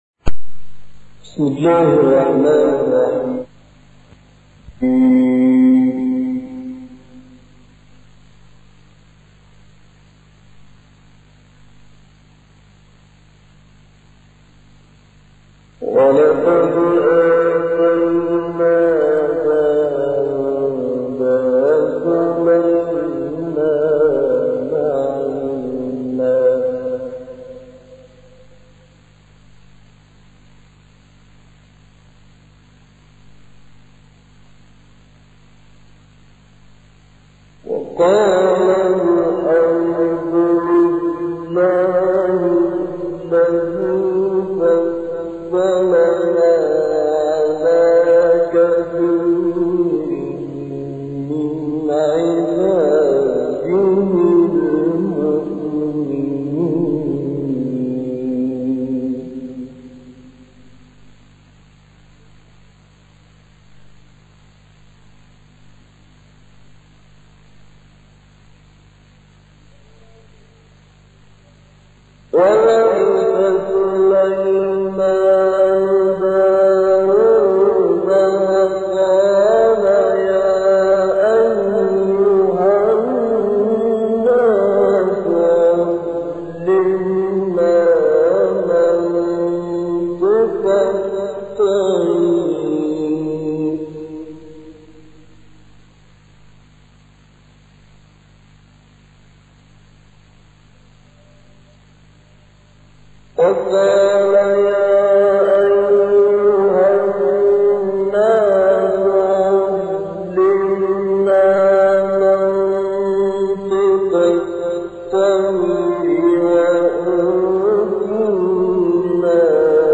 تلاوتی از سوره نمل و تلاوتی از سوره حاقه